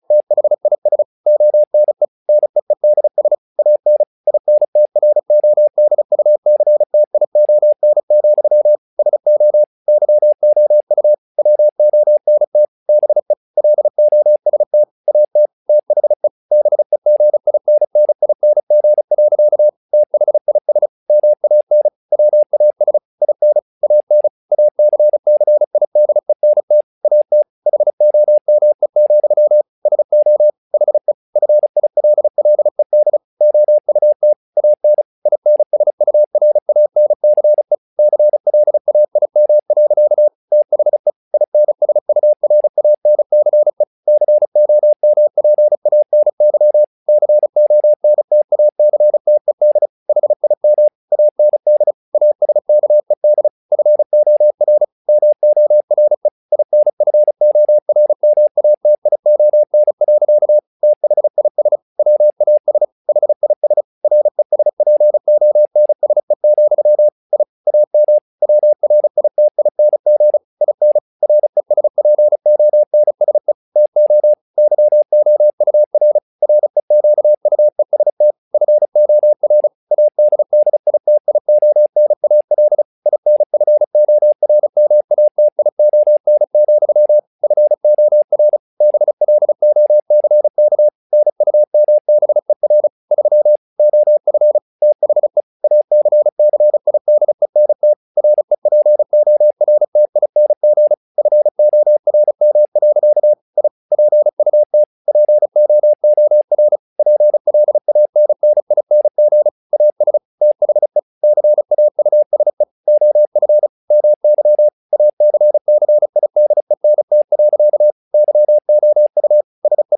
Audio files at various WPM speeds (10-40 WPM).
tower_trouble_35wpm.mp3